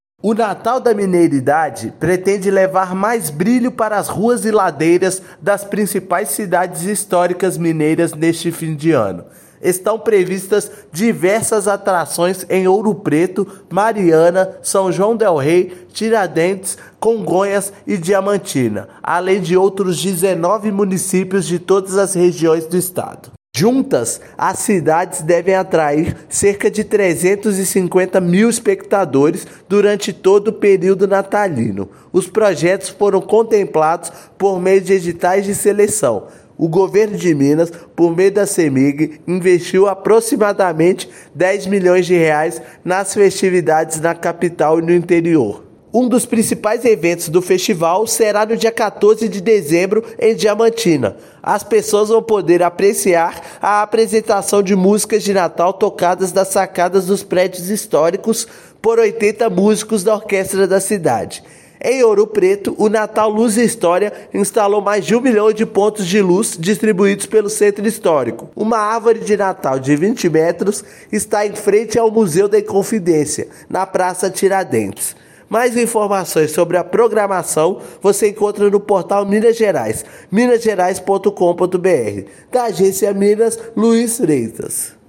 [RÁDIO] Natal da Mineiridade deve atrair mais de 350 mil pessoas nas cidades históricas
Programações das festas estão cheias de atrações de dança, música e artes para os moradores locais e turistas. Ouça matéria de rádio.